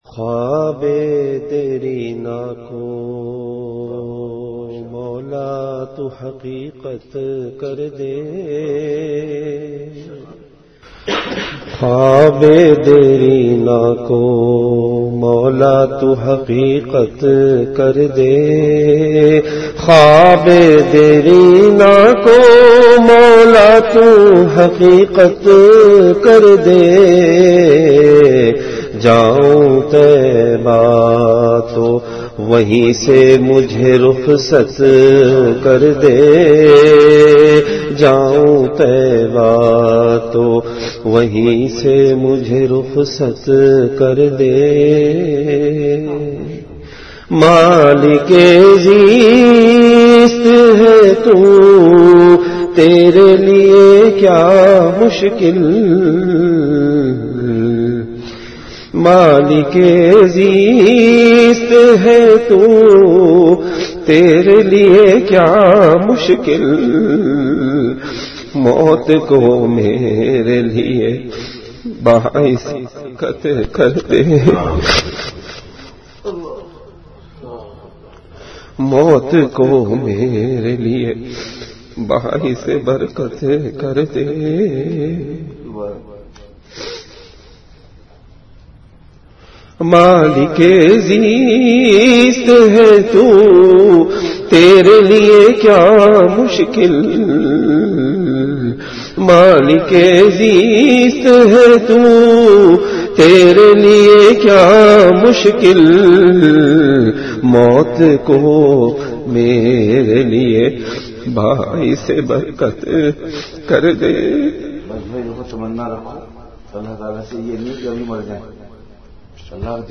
Delivered at Madinah Munawwarah.